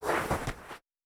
Pitch Movement Intense Slide.wav